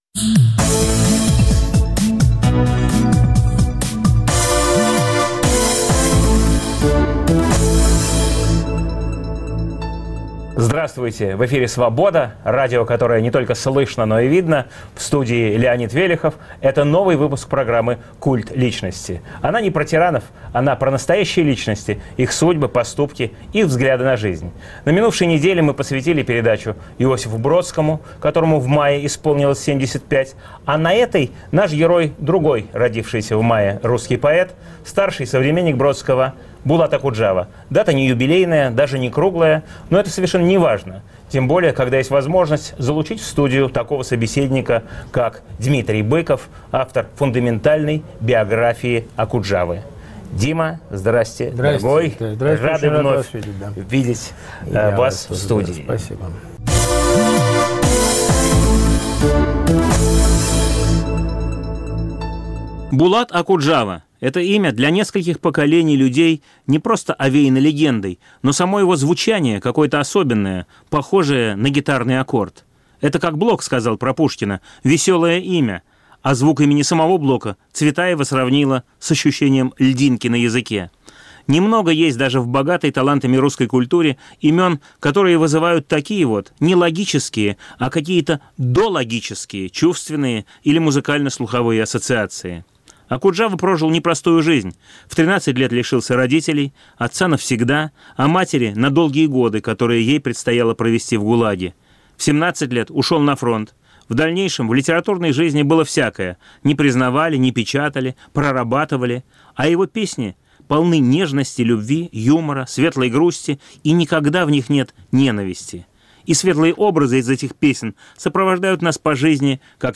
Новый выпуск программы о настоящих личностях, их судьбах, поступках и взглядах на жизнь. В студии "Культа личности" поэт Дмитрий Быков,автор монографии о жизни и творчестве Окуджавы . Эфир в субботу 30 мая в 18 часов Ведущий - Леонид Велехов.